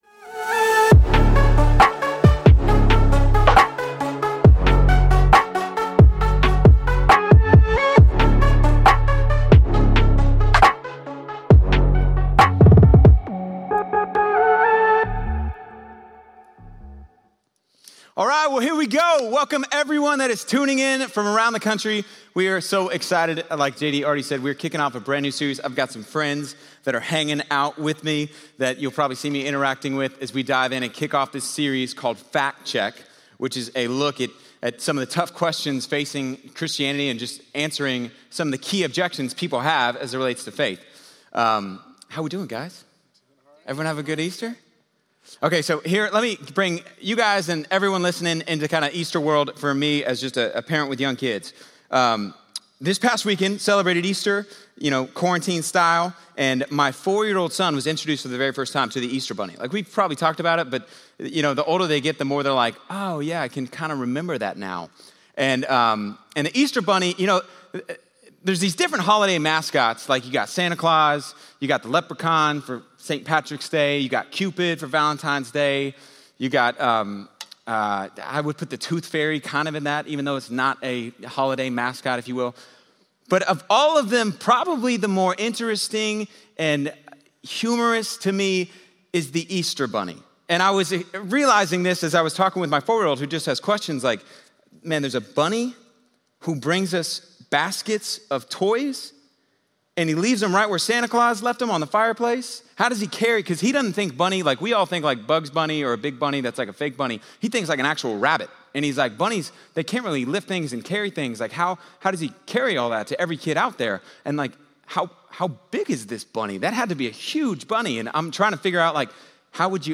In this message, we learn how Christianity explains how bad things happen and actually points to the fact that God is still good.